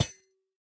Minecraft Version Minecraft Version 1.21.5 Latest Release | Latest Snapshot 1.21.5 / assets / minecraft / sounds / block / copper_grate / step3.ogg Compare With Compare With Latest Release | Latest Snapshot